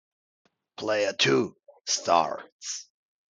Caller Sound